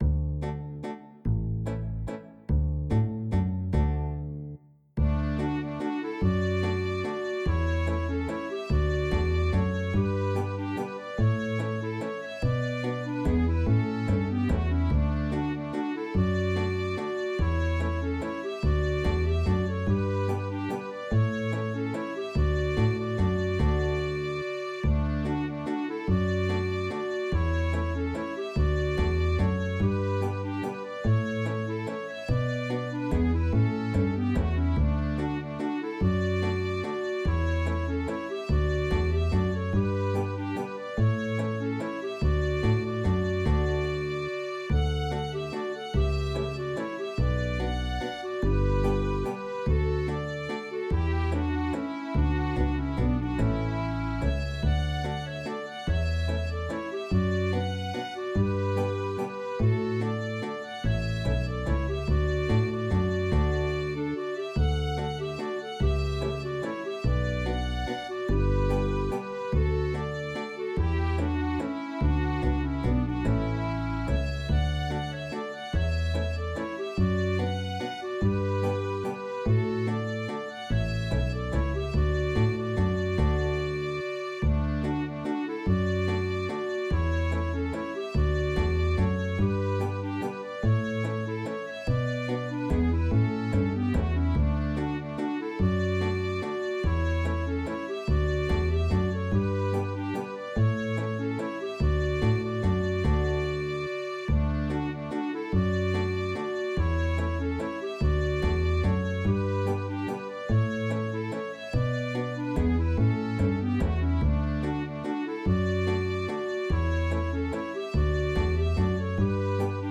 Hej, vill du bidra med en video till denna vals?
D-dur: